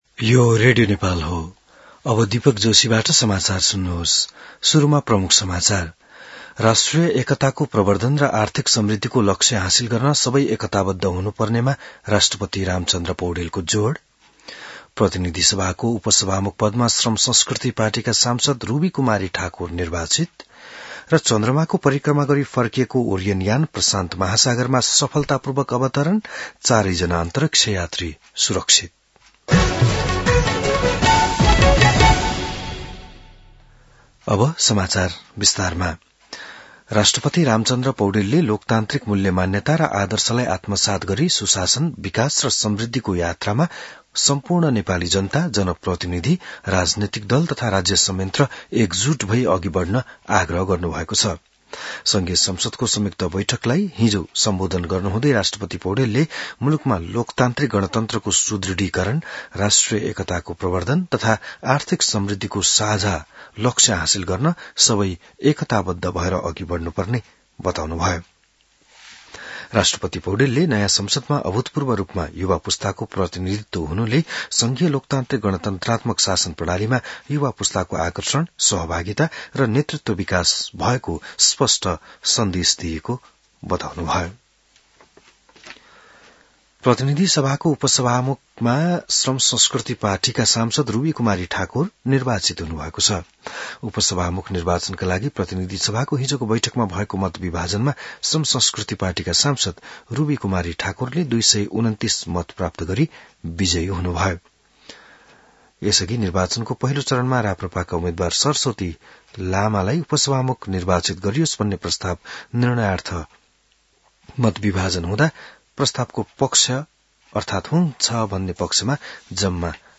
बिहान ९ बजेको नेपाली समाचार : २८ चैत , २०८२